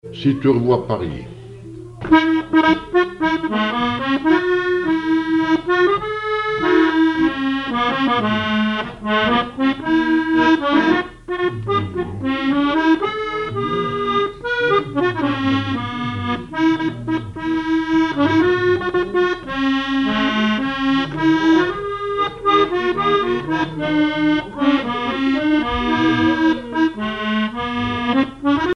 accordéon(s), accordéoniste
danse : marche
Pièce musicale inédite